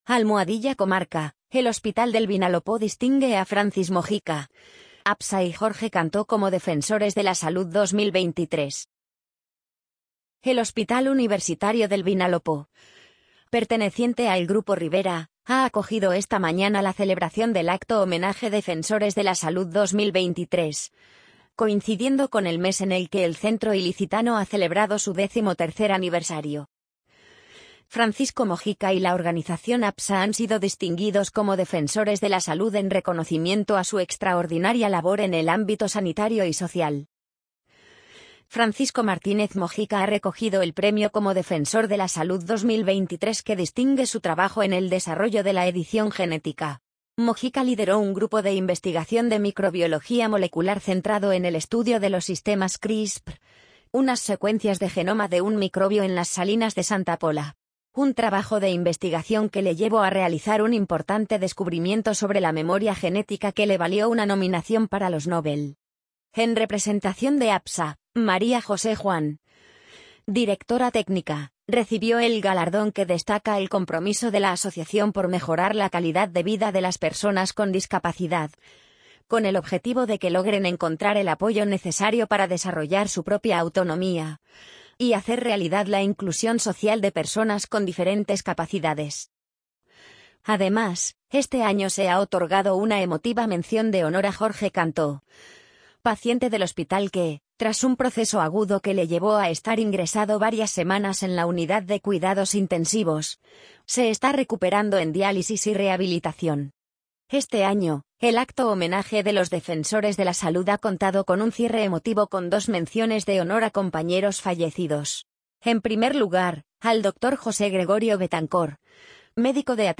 amazon_polly_67001.mp3